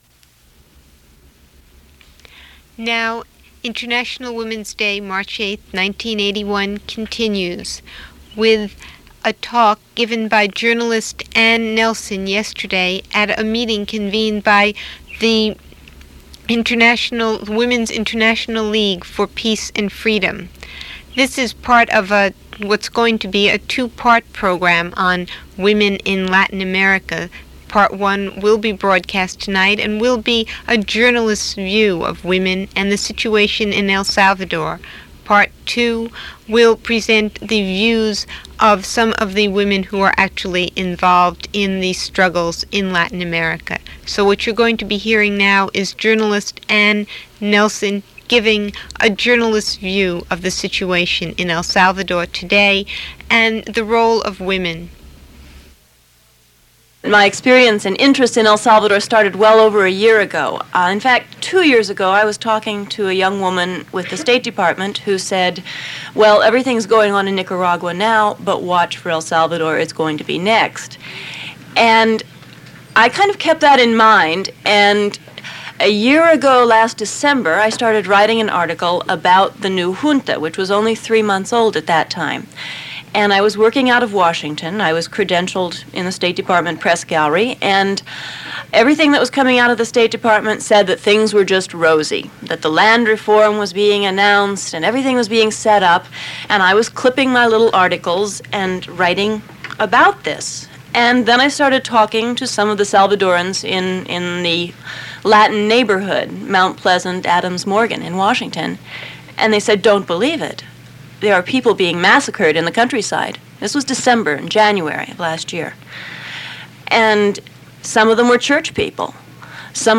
(This is Side A of tape) Part one of a program on Latin-American women, features a recorded lecture